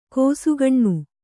♪ kōsugaṇṇu